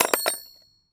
metal_small_movement_04.wav